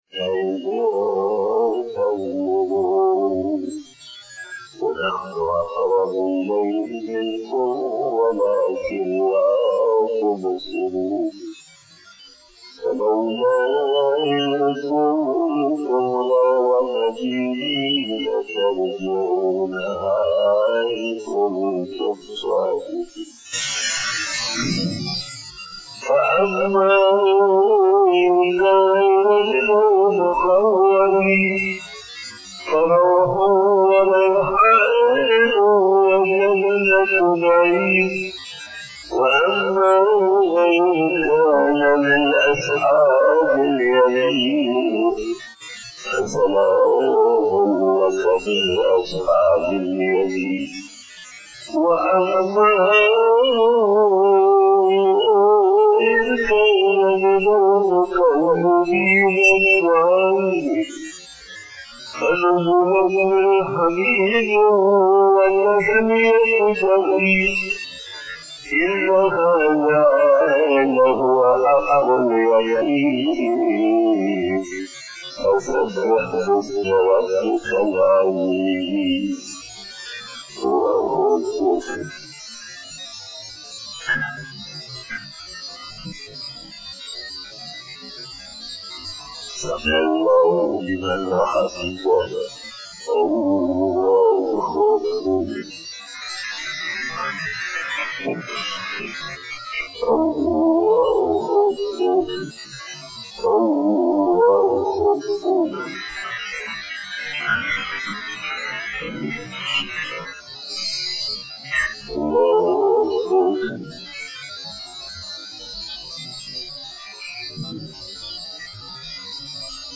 بیان بعد نماز فجر بروز بدھ 15 رجب المرجب 1441ھ/ 11 مارچ 2020ء